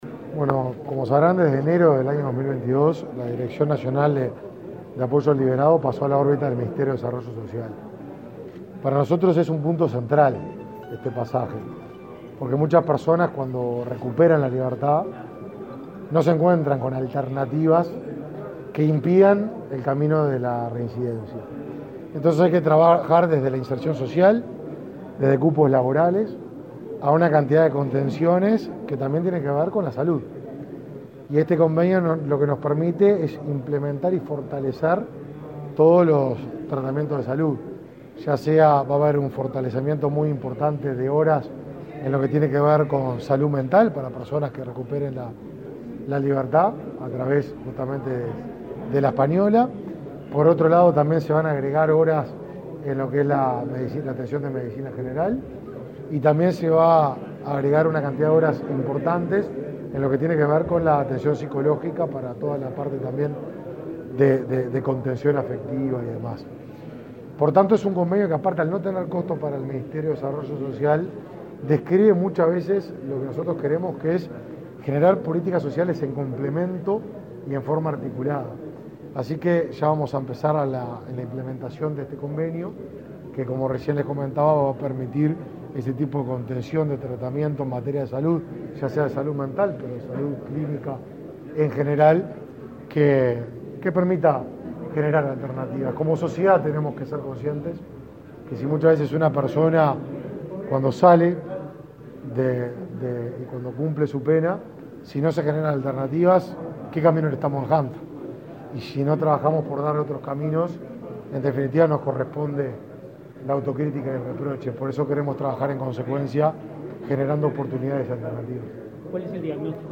Declaraciones a la prensa del ministro de Desarrollo Social
El ministro de Desarrollo Social, Martín Lema, dialogó con la prensa luego de firmar un acuerdo con directivos de la Asociación Española para la